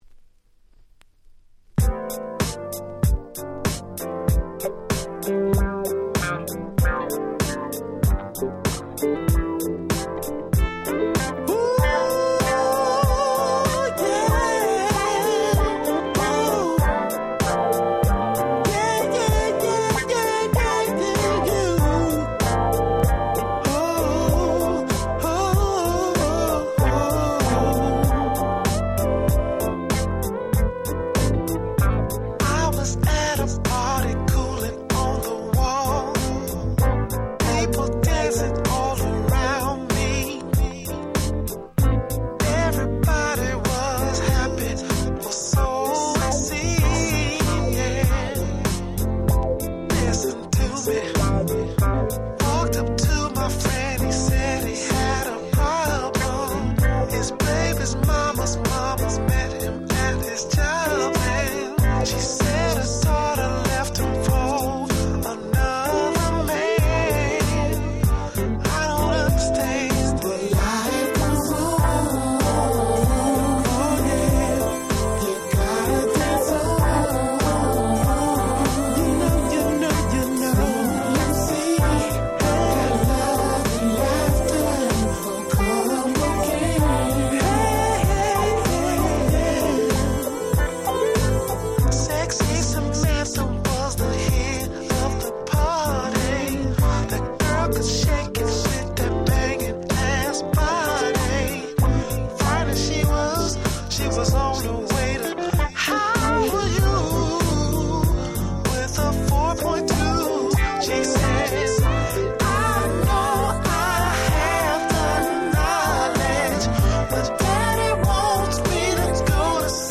Bay Areaのマイナーゴスペルグループによるドヤバイ1発！！